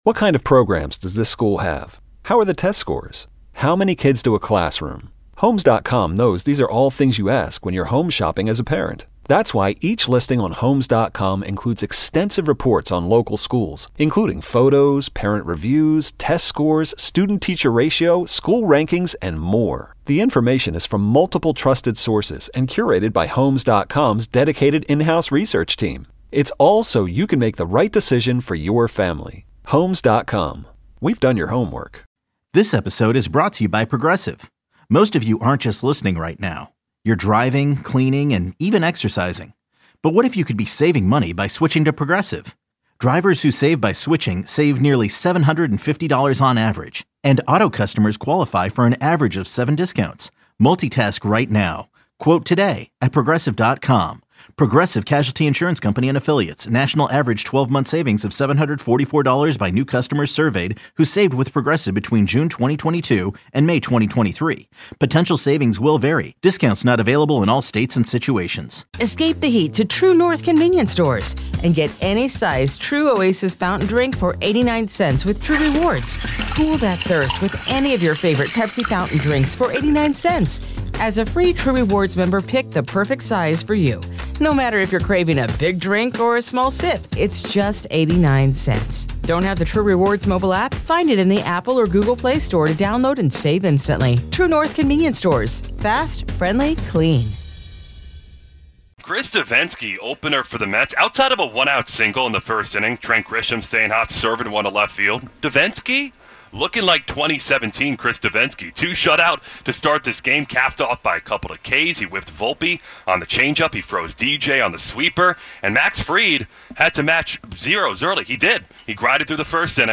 In a candid interview